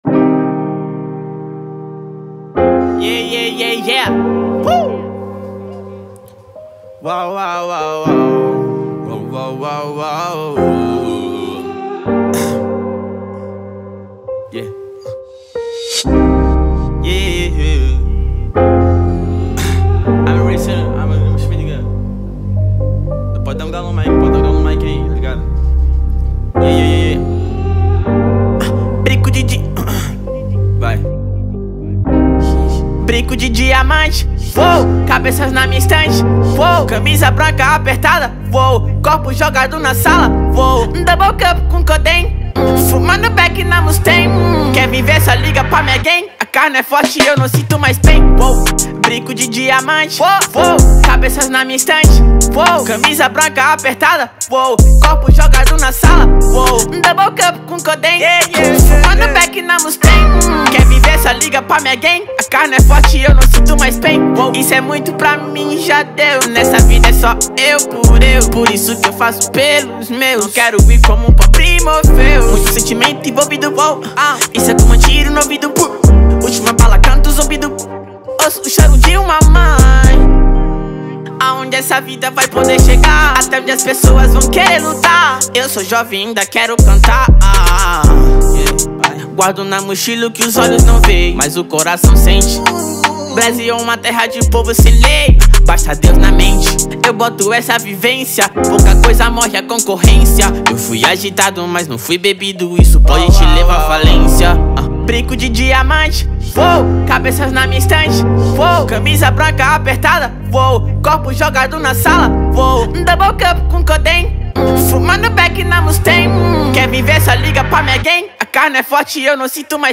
2025-01-27 23:56:09 Gênero: Trap Views